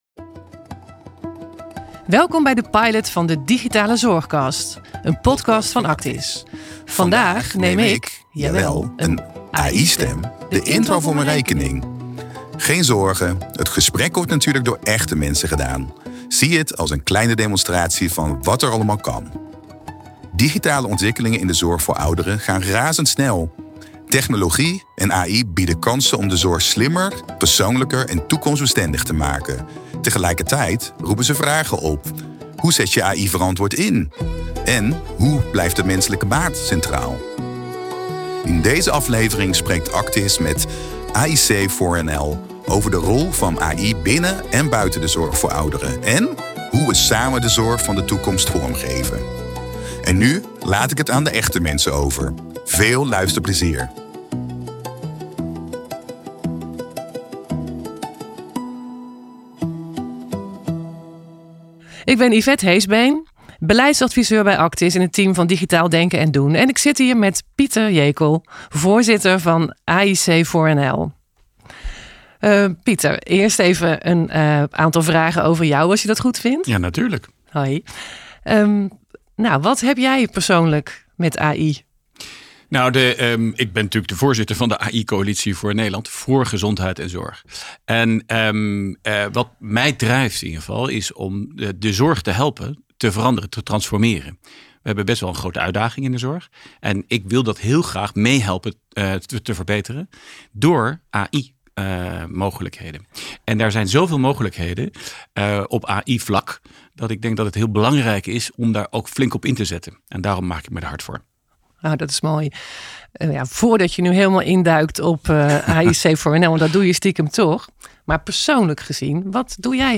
In deze podcast spreken we met experts binnen én buiten de sector over digitale ontwikkelingen die de zorg voor ouderen versterken en veranderen.